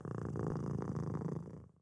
Cat Sound Effect
cat-1.mp3